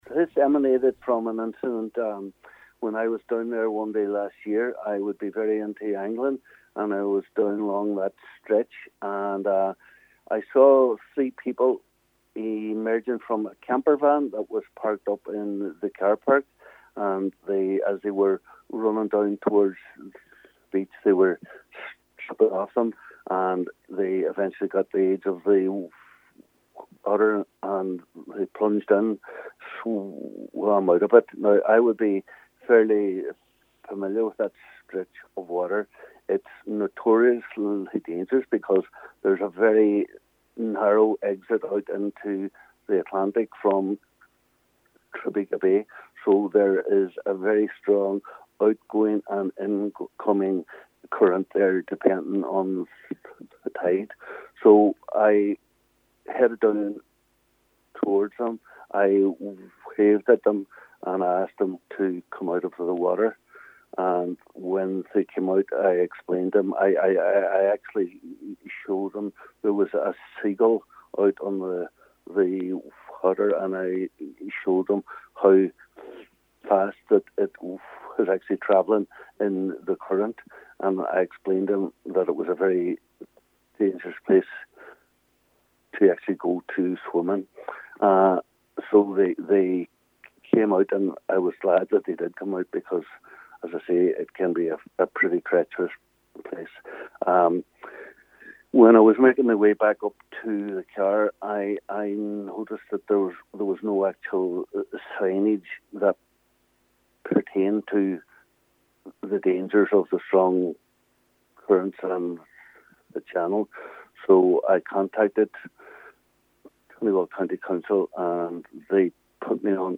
He says it is extremely dangerous, particular those who are unaware of the dangers: